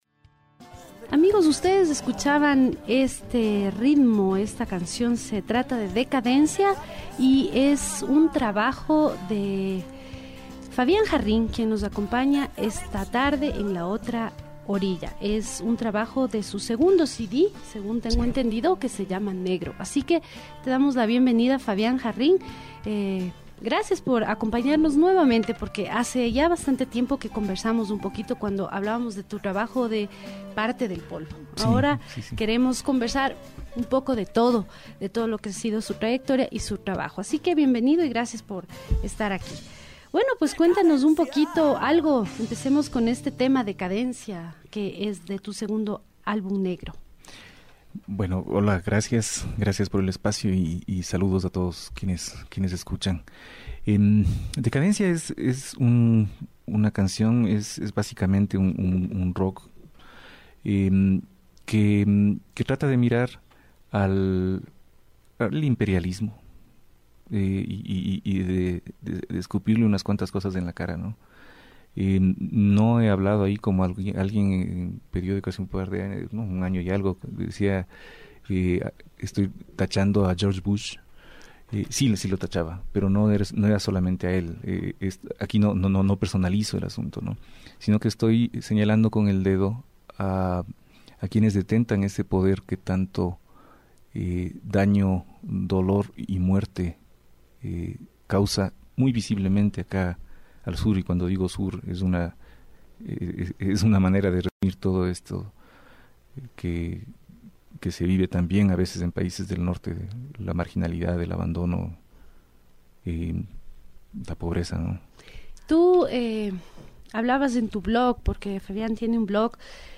Aquí pueden escucharse y leerse unas pocas entrevistas que, gracias al logrado oficio de quienes las condujeron, terminaron siendo conversaciones que suscitaron buenos comentarios.